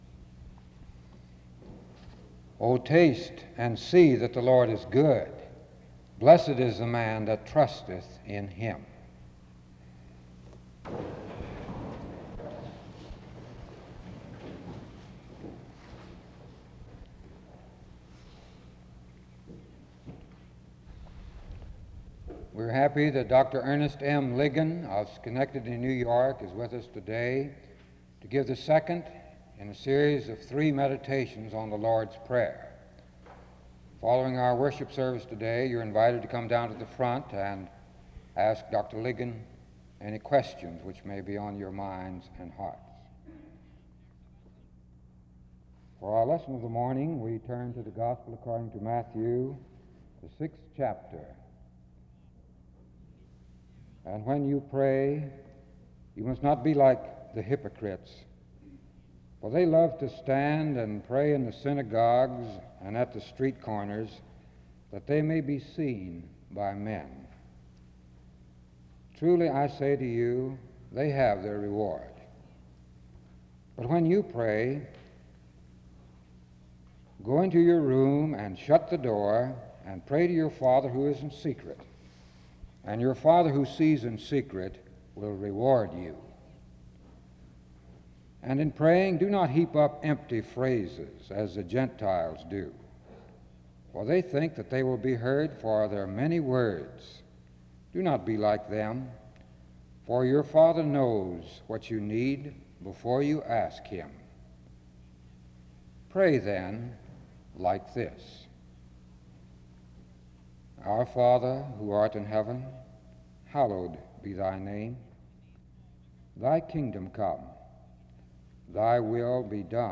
Download .mp3 Description The service begins with the reading of Psalm 34:8 (00:00-00:21).
He ends in prayer (22:23-24:12), and the service ends with the singing of Numbers 6:24-26 (24:13-25:26).